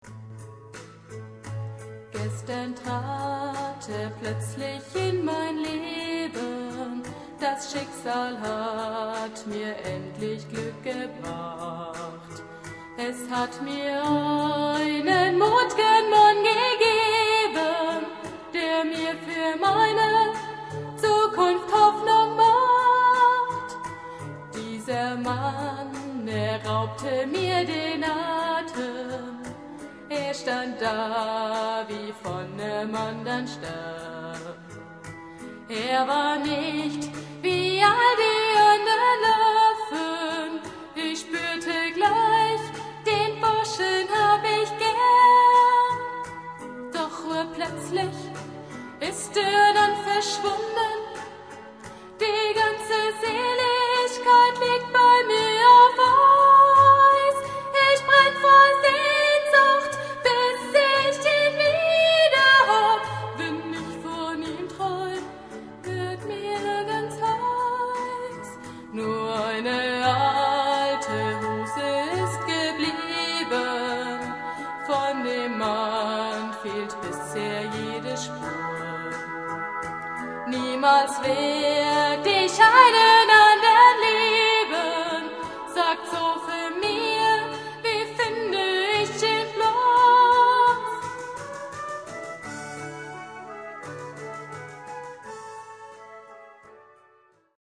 Musical mit Anspruch, romantisch, schwungvoll, lustig, abwechslungsreich
Aufgeführt im Januar und Mai 1996 am Anton Fingerle Bildungszentrum München,
Flöten:
Klarinette:
Saxophone:
Xylophone: